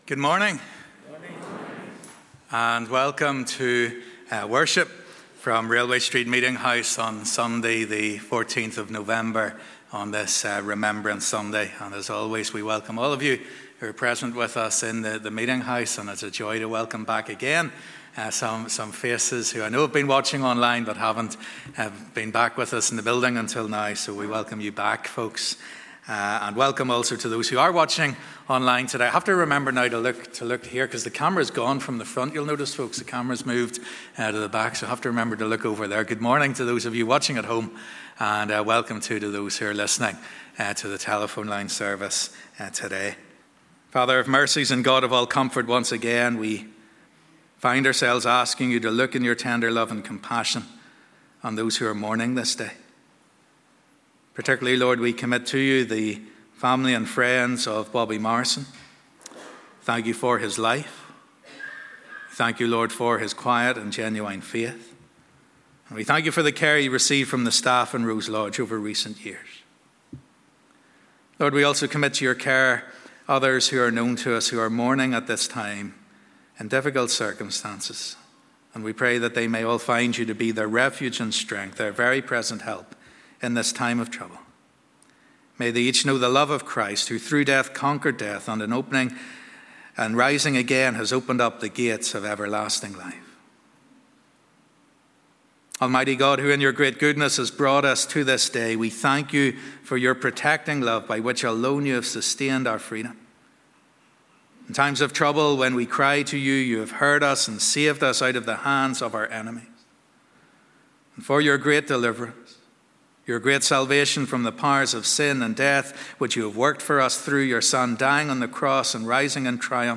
Morning Service